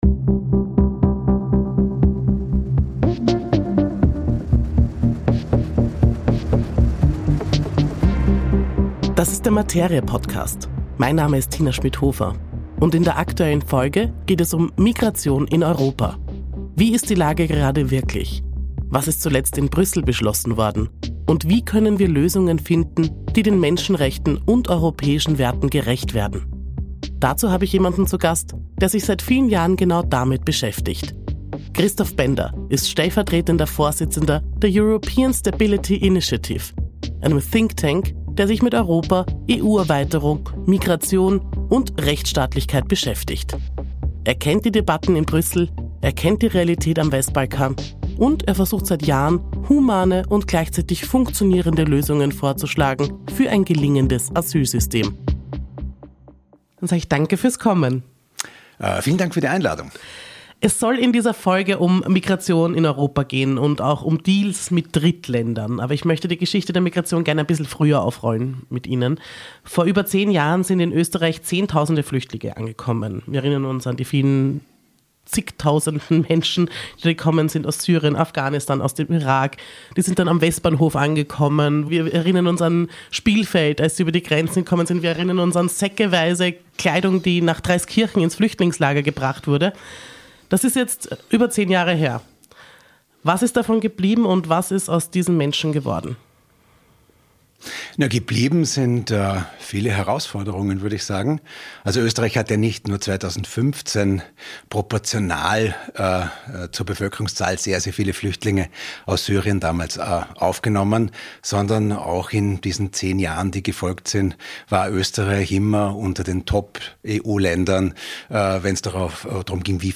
Ein Gespräch über rote Linien, die Europa nicht überschreiten darf, und darüber, wie eine Migrationspolitik aussehen könnte, die 2036 geordneter, fairer und menschlicher ist als heute.